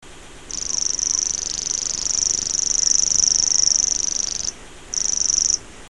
Forapaglie macchiettato
Locustella naevia
Richiamo ‘tuit’ o ‘pit’. Canto un alto e monotono ‘rrrrrrrrrrr’, più lungo che nella Salciaiola e così acuto che talvolta le persone più anziane non lo possono sentire.
Forapaglie-macchiettato.mp3